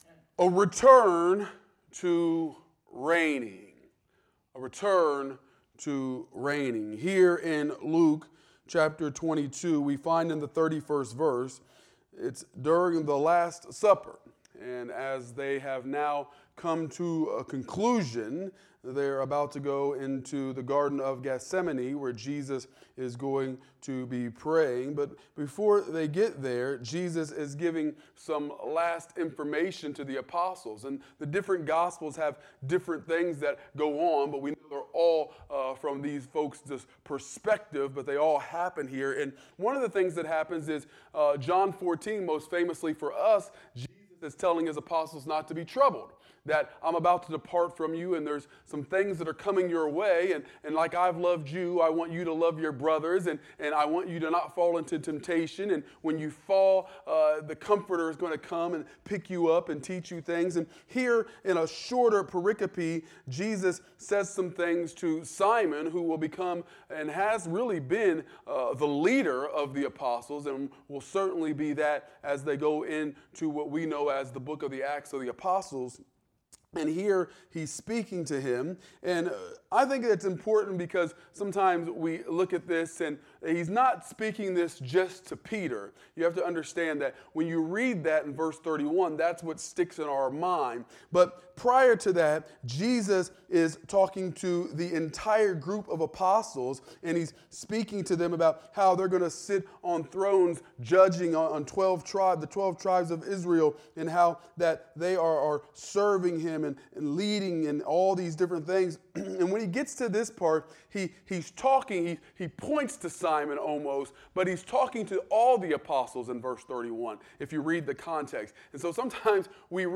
Wednesday Bible Study